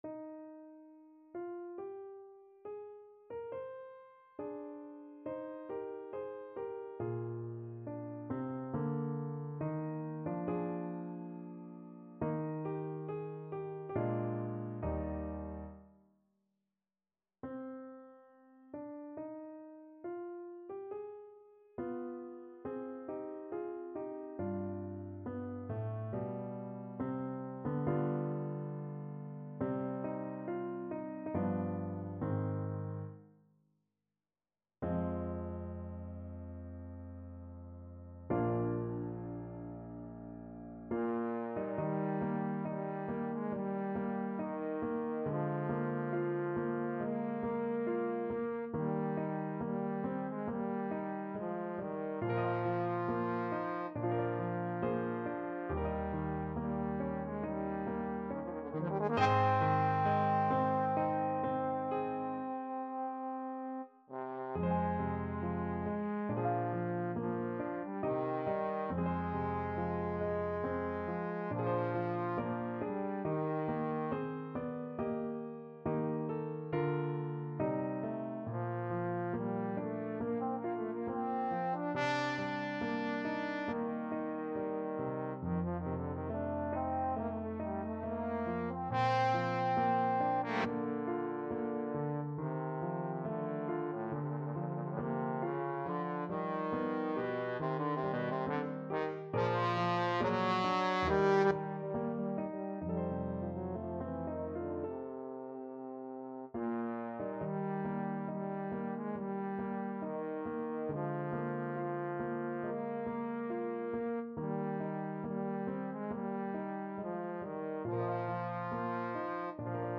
4/4 (View more 4/4 Music)
Larghetto (=80) =69
A3-D5
Classical (View more Classical Trombone Music)